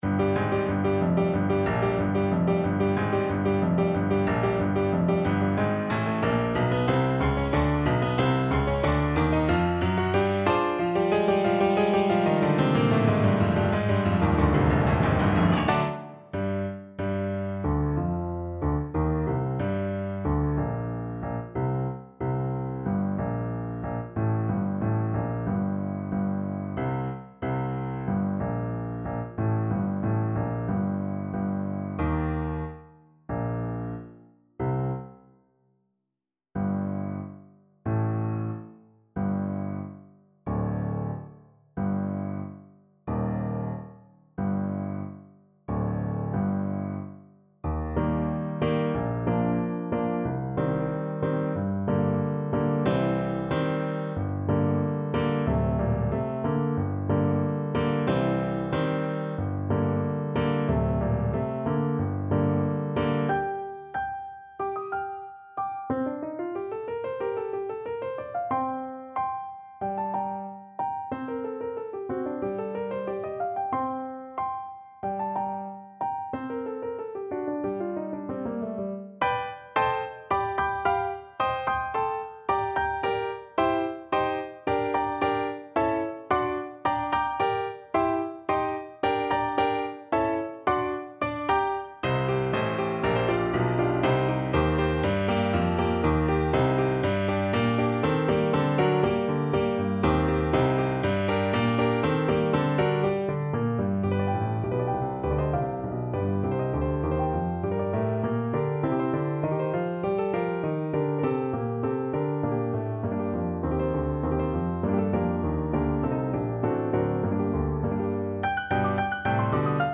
Allegro =92 (View more music marked Allegro)
2/4 (View more 2/4 Music)
Classical (View more Classical Clarinet Music)